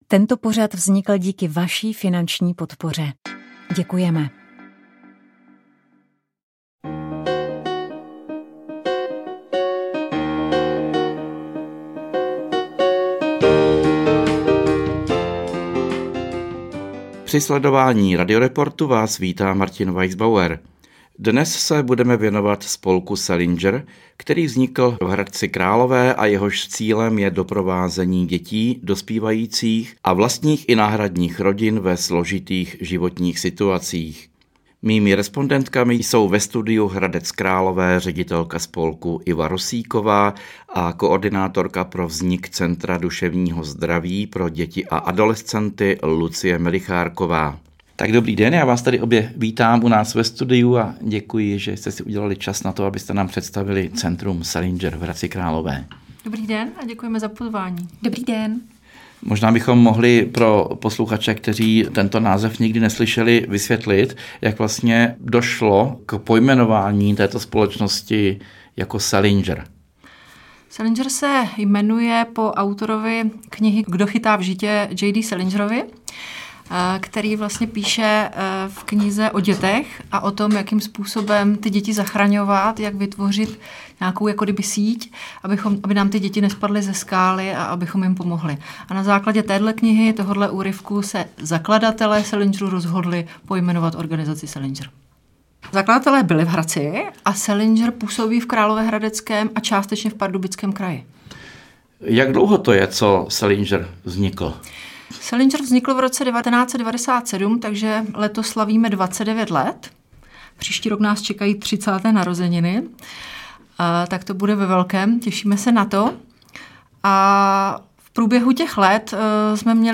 Lidová píseň Při trenčanskej bráně v podání Cimbálové muziky Líšňáci, která otevírá dnešní radioreport, zazněla i na zahájení Slováckého plesu v Brně-Králově poli.